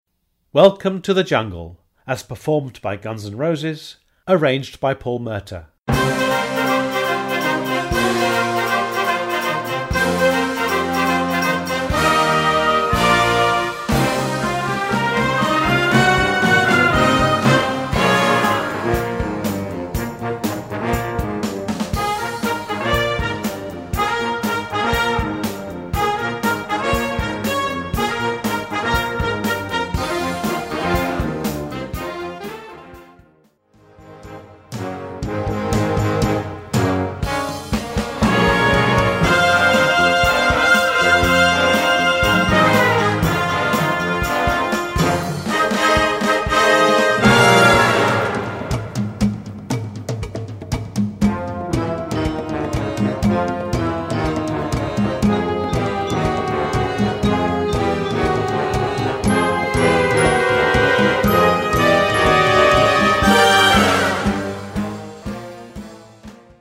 Category: POP & ROCK TUNES - Grade 3.0